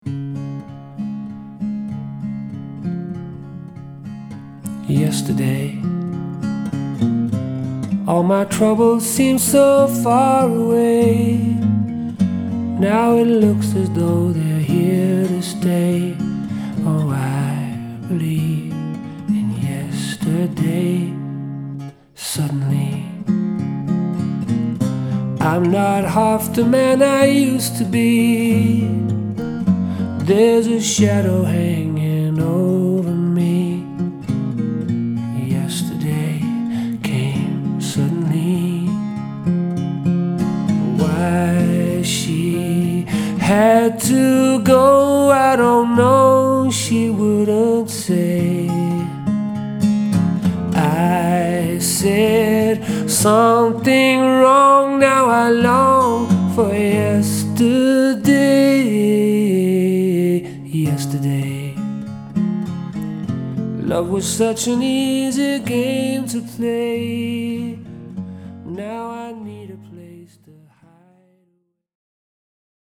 • Unplugged
• Sänger/in